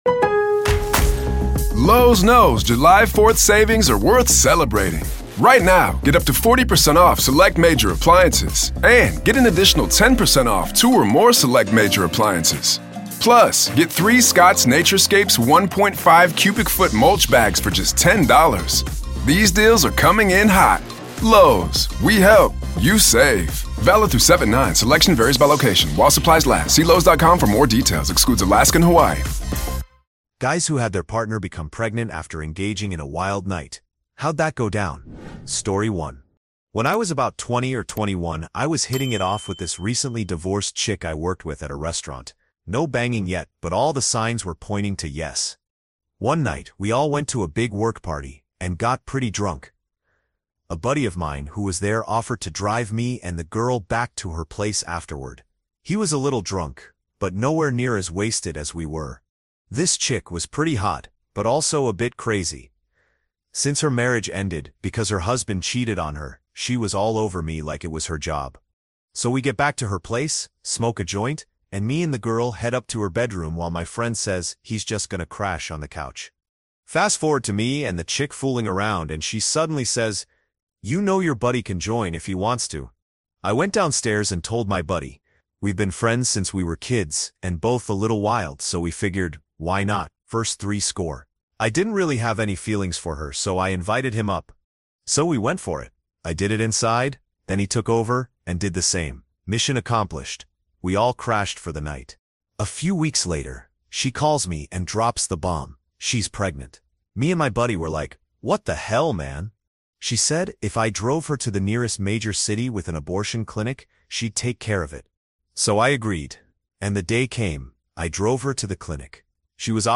1 The One With The Garth Brooks Serial Killer Conspiracy (Allegedly) 35:41 Play Pause 4h ago 35:41 Play Pause Play later Play later Lists Like Liked 35:41 Send us a text 🎙 Two girlfriends.